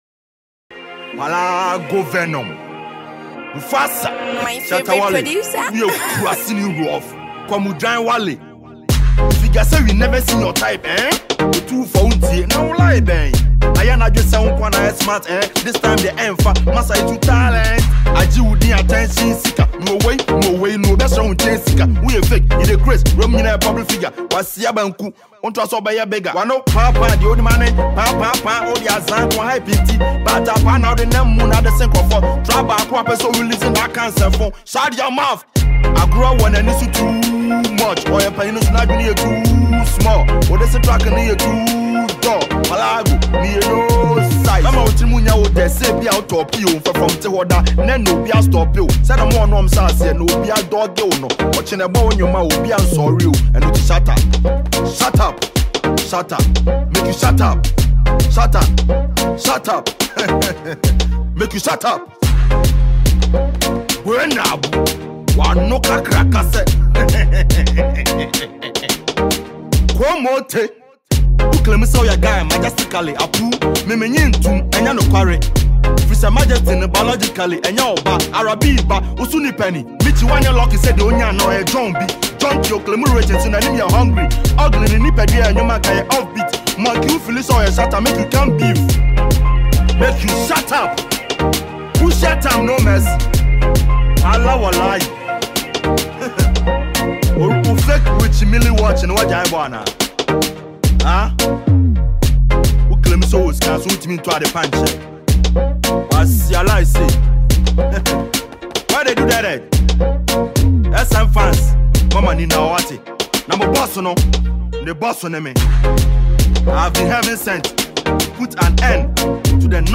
Ghanaian singer/rapper
diss song